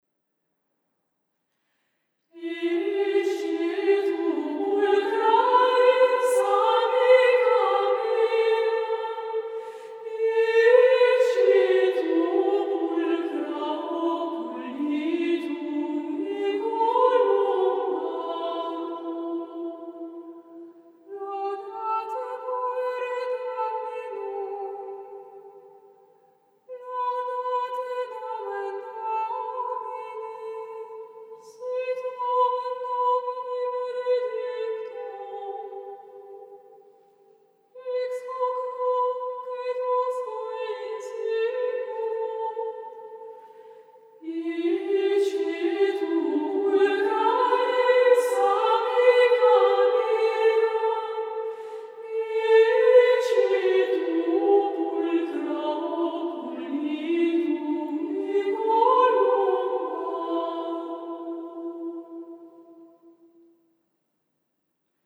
Antiphona Ecce tu pulchra es (Hld 1,14) Psalmus Laudate pueri Dominum (Ps 112/113) [1:05] (KD 35, 1 CD) (UNIVERSITÄTSBIBLIOTHEK - ARCHIV UND MUSIKINSTRUMENTENSAMMLUNG - o:134033)